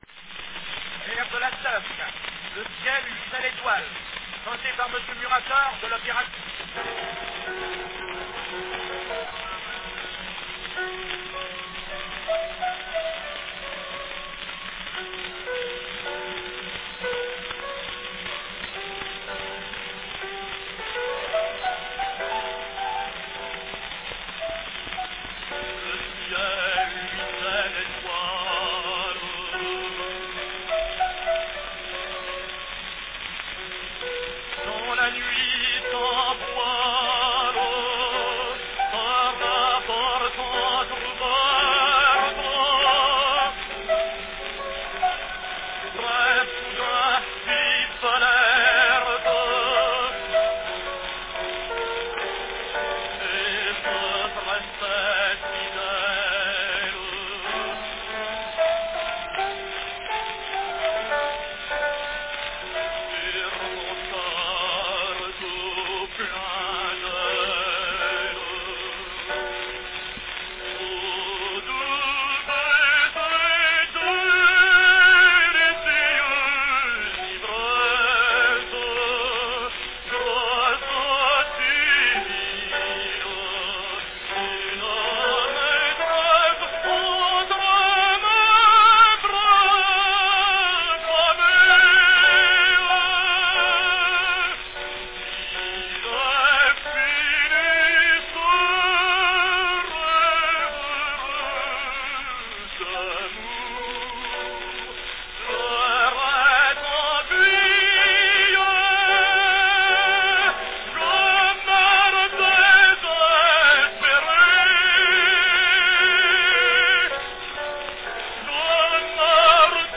An early recording from Puccini's Tosca, sung by French tenor Lucien Muratore.
Cylinder # 3728 (3½" diameter)
Category Tenor
Performed by Lucien Muratore
Announcement "Air de La Tosca - Le ciel luisait d'etoiles - chanté par Monsieur Muratore de l'opéra."
On this unusual format 3½" diameter "salon" cylinder we hear, in one of his earliest sessions with Pathé, a youthful Muratore as Tosca's doomed lover, Mario Cavaradossi, singing a French version of "and the stars were shining" from the opera's final act.